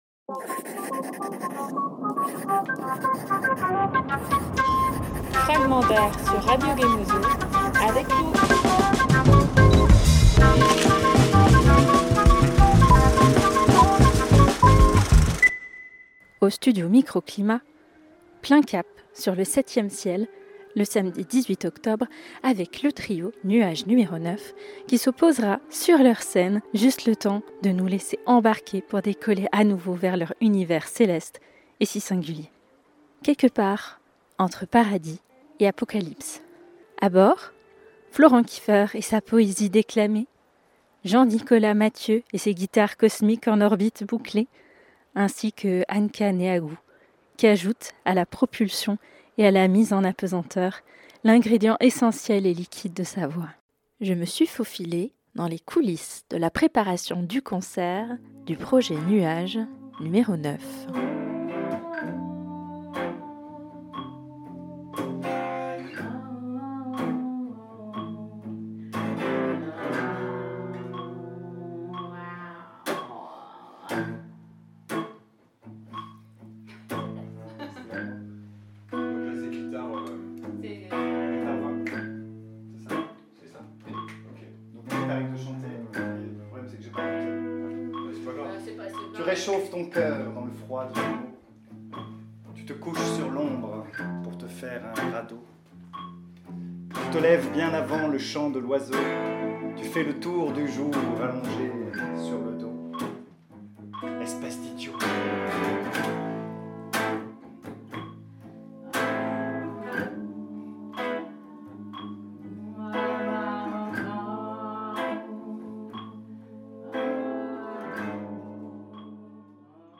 Une immersion poétique et sonore, suspendue entre texte, musique et nuage.